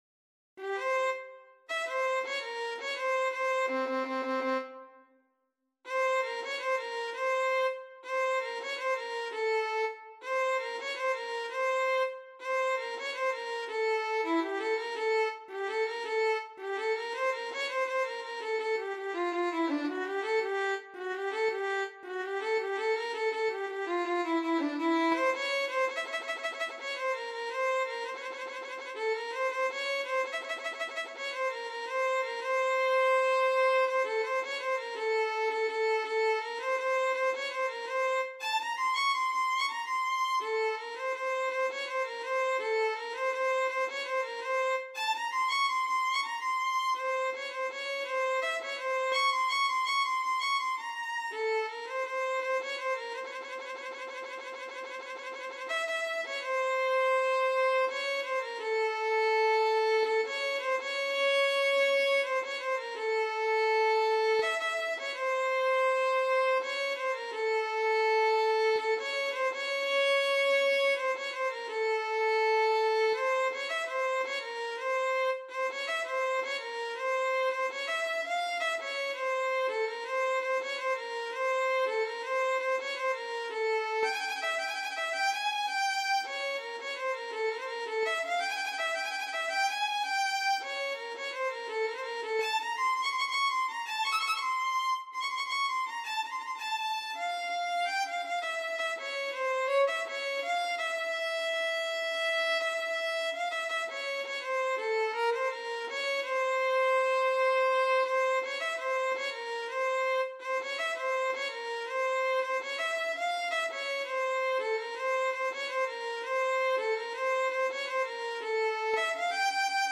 ویولون
تنظیم برای ویولن